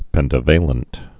(pĕntə-vālənt)